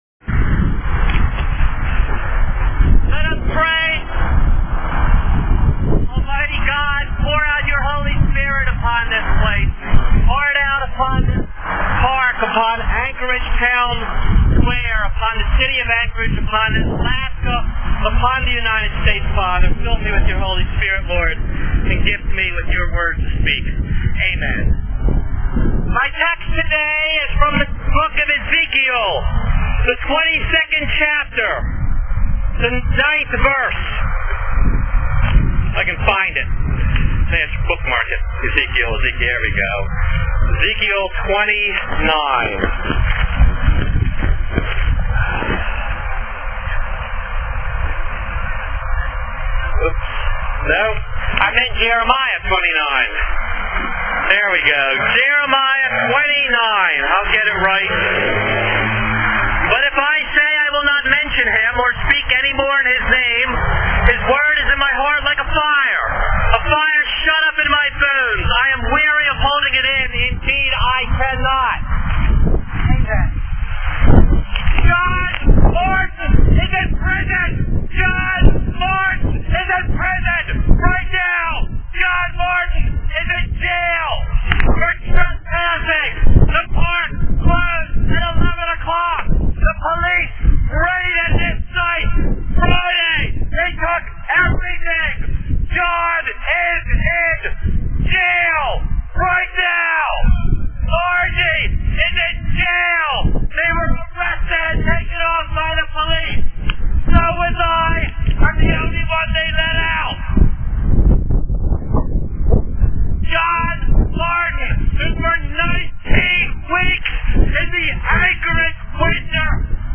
I sat on the sidewalk, in compliance with the trespass order, but I entered the park to preach, and I intend to preach every hour, on the hour.